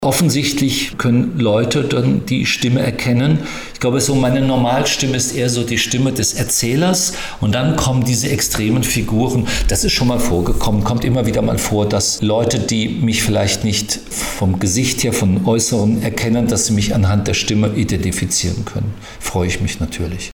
25 Jahre Harry Potter - Rufus Beck im Interview - PRIMATON